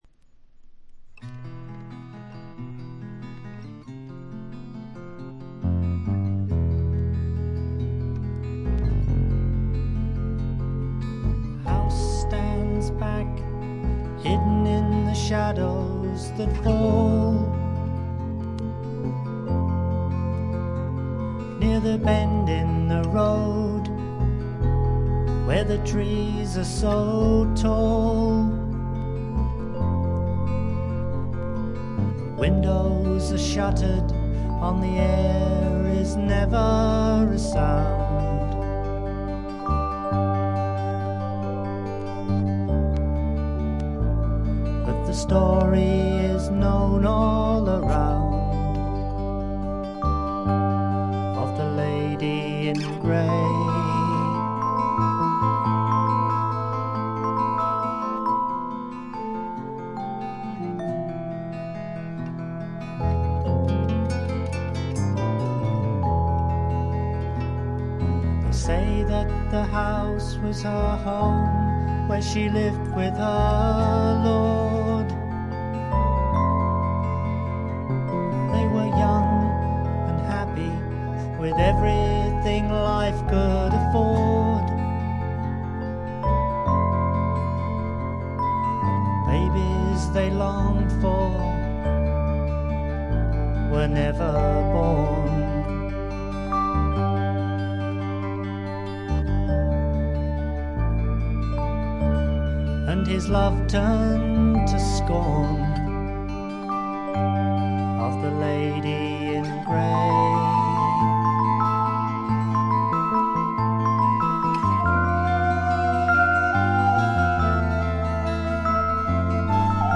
ホーム > レコード：英国 SSW / フォークロック
ところどころで軽いチリプチ程度。
少しざらついた美声がとても心地よいです。
試聴曲は現品からの取り込み音源です。